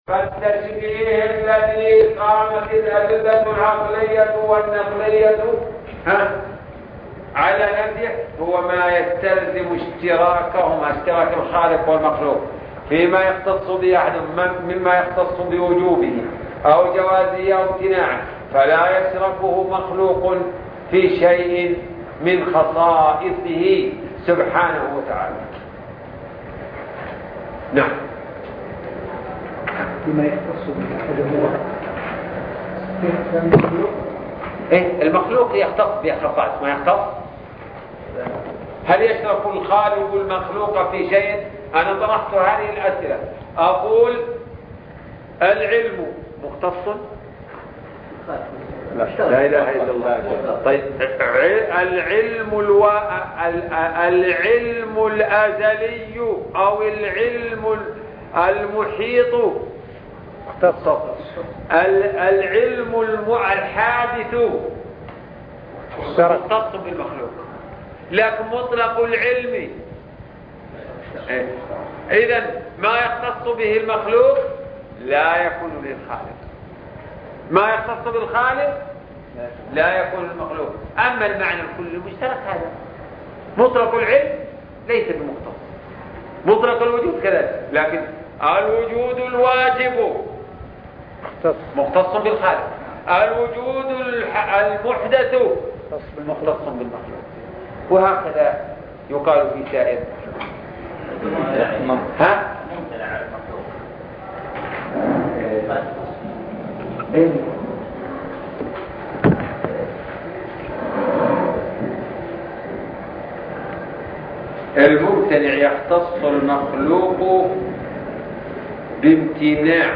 شرح الرسالة التدمرية (13) الدرس الثالث عشر - الشيخ عبد الرحمن بن ناصر البراك